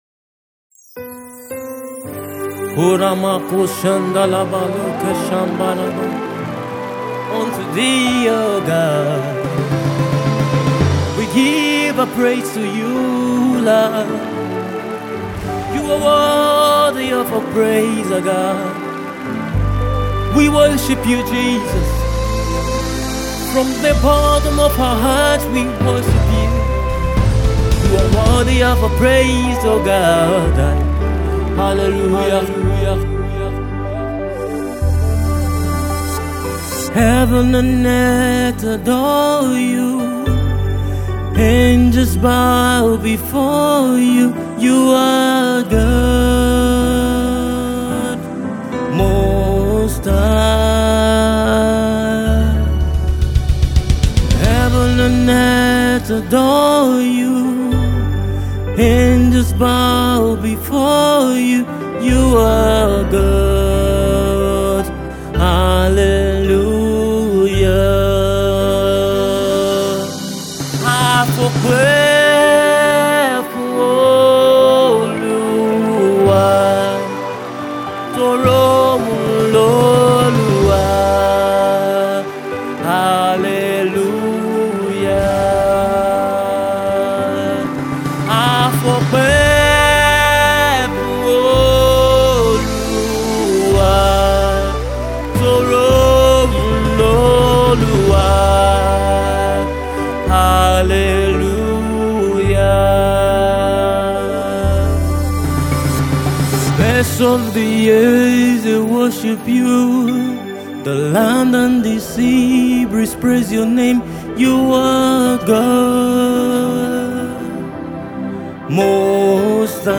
a worship song written in English and Yoruba
with a soul lifting sound.